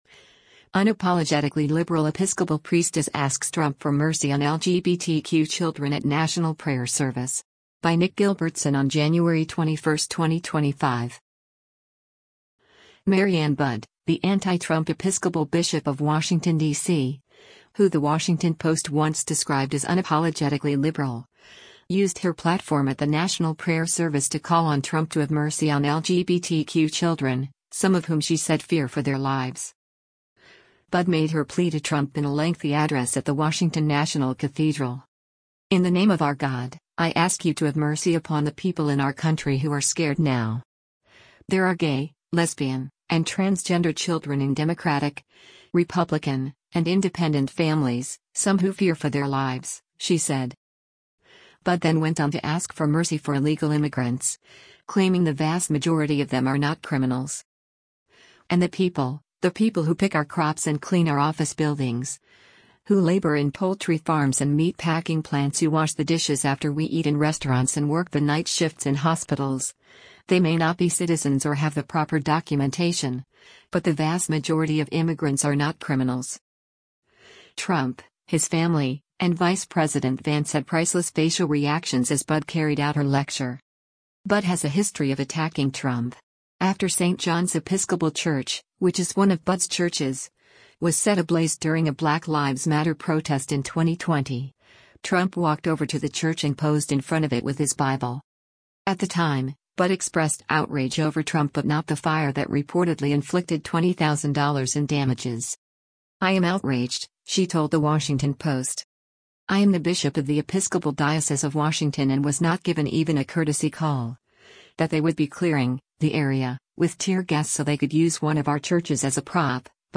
Budde made her plea to Trump in a lengthy address at the Washington National Cathedral.